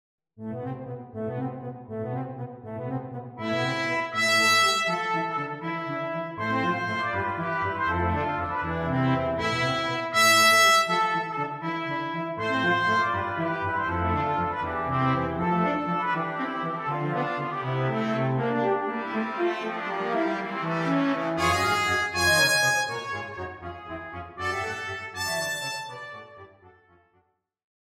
III  Very fast
Excerpt from Third Movement (Brass)